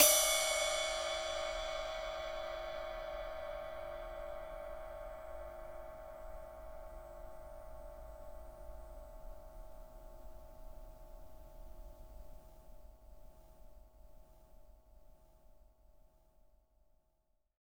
• Ambient Ride Single Shot D Key 02.wav
Royality free ride cymbal one shot tuned to the D note. Loudest frequency: 4810Hz
ambient-ride-single-shot-d-key-02-Kjr.wav